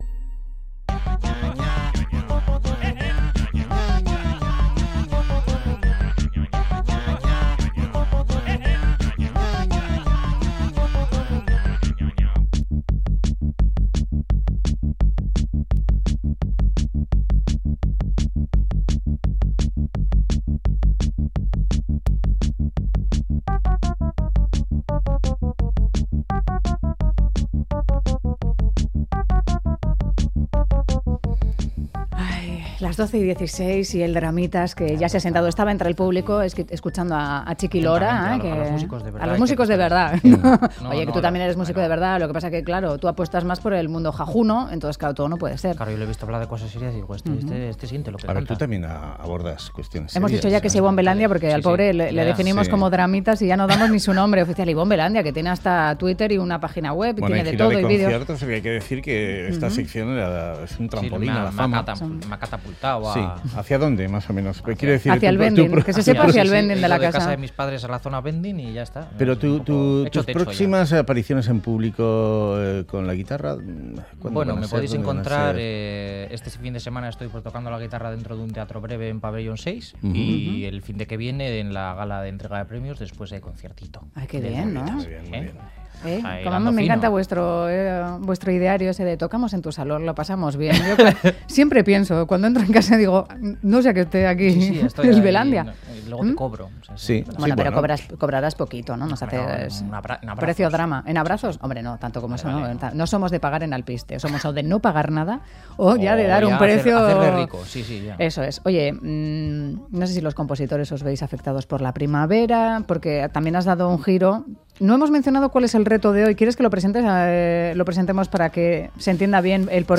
Viernes a ritmo de swing